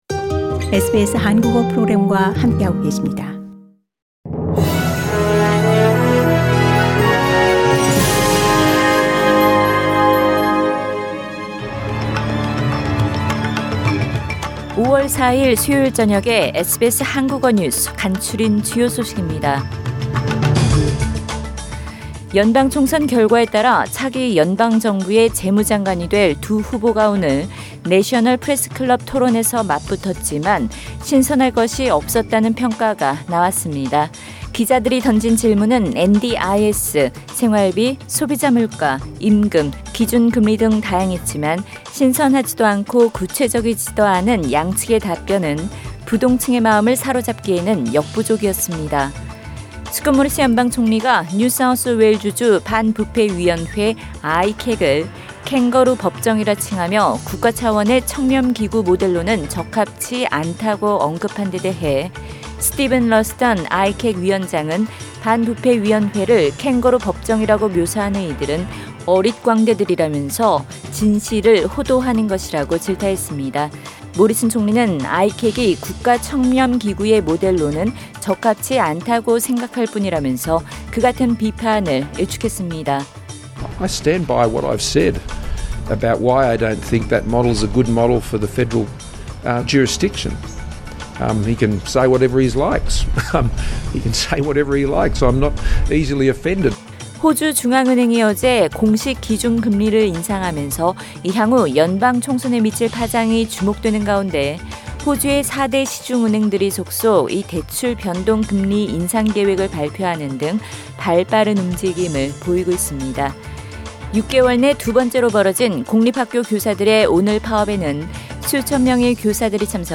SBS 한국어 저녁 뉴스: 2022년 5월 4일 수요일